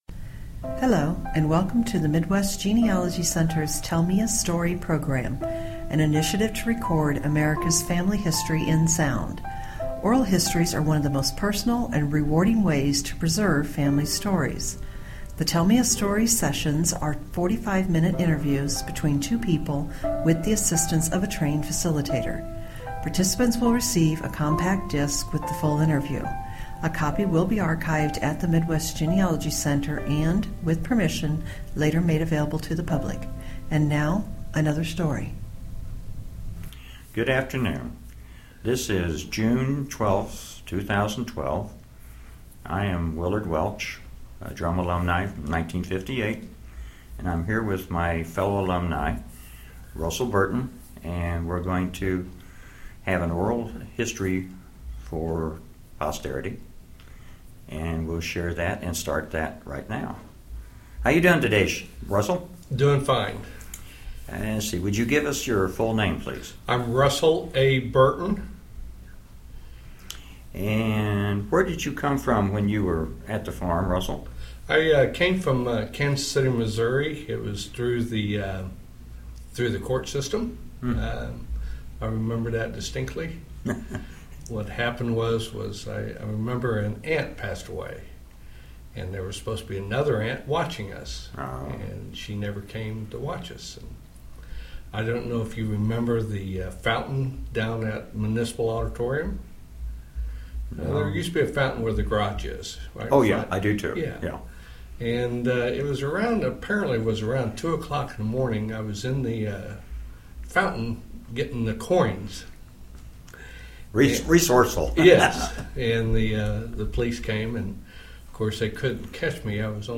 Drumm Institute Oral Histories